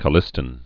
(kə-lĭstĭn, kō-)